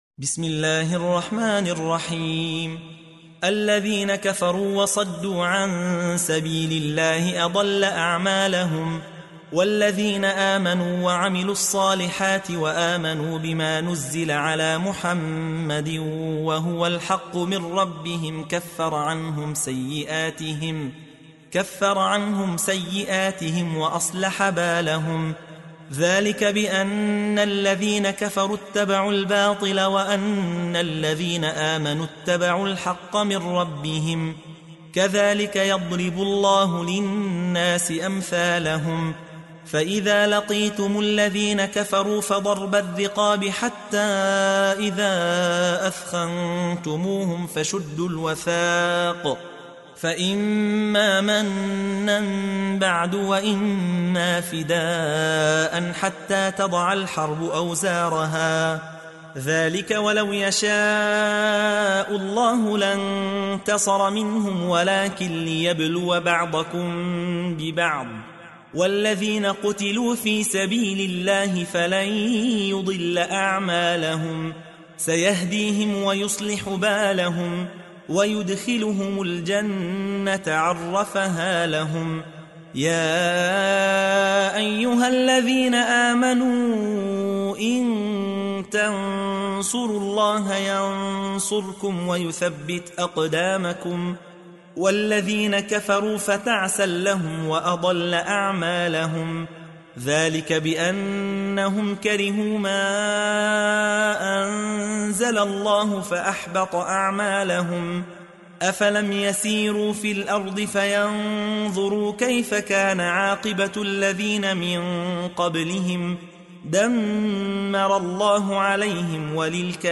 تحميل : 47. سورة محمد / القارئ يحيى حوا / القرآن الكريم / موقع يا حسين